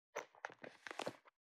443物を置く,バックを置く,荷物を置く,
効果音室内物を置く
効果音